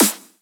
normal-hitnormal.wav